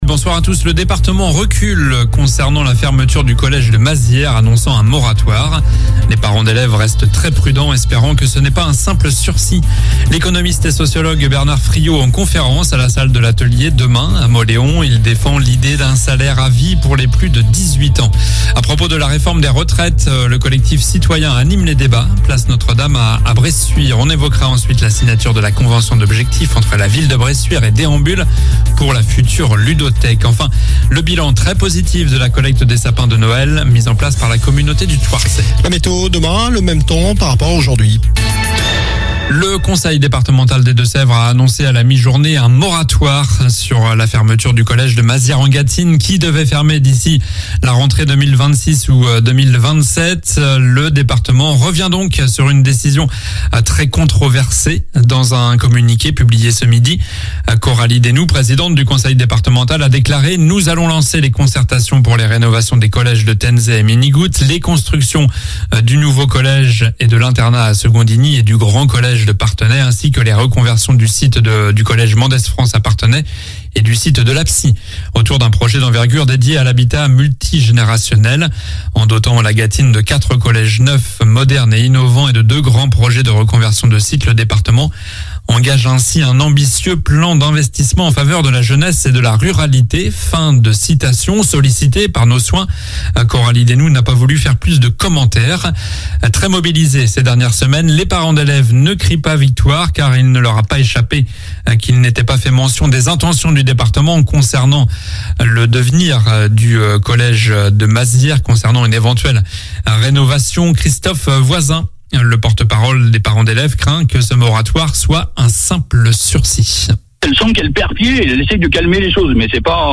Journal du jeudi 9 février (soir)